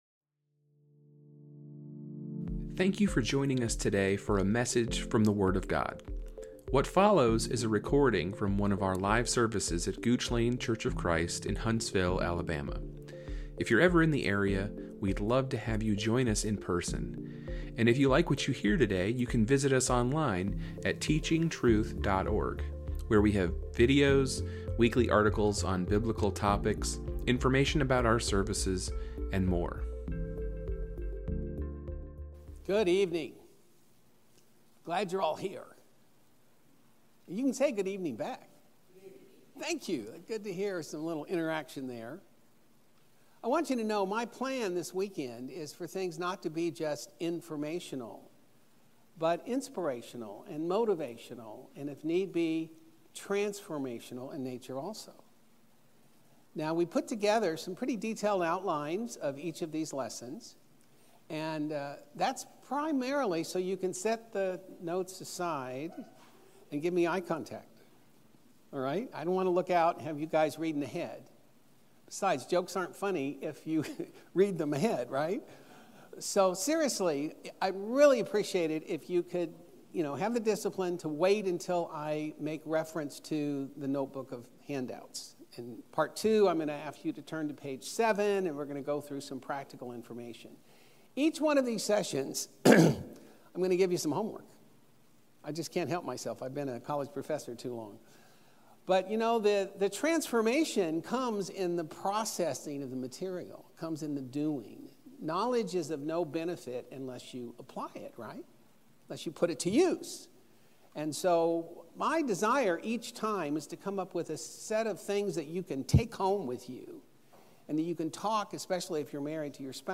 Lesson 1 of our 8-part gospel meeting